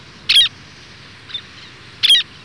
LeastTern-Sterna-antillarum.wav